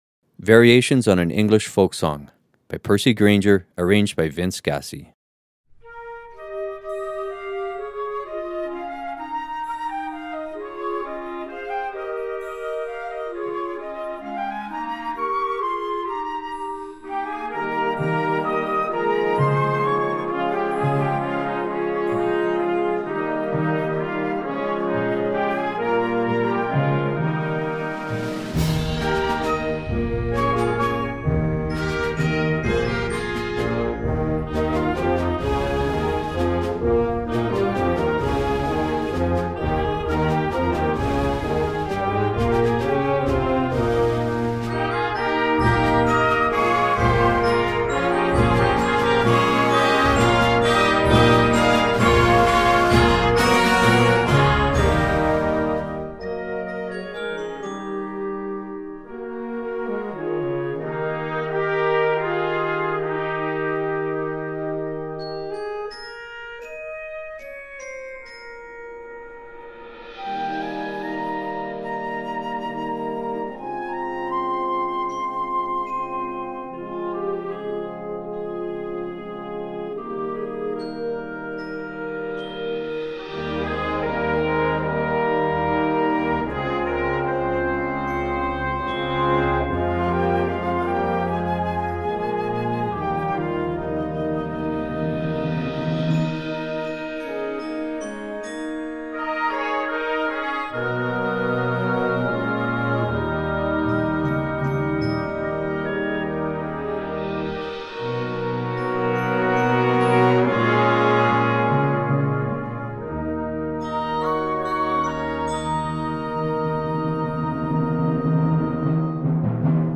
This simple, yet appealing setting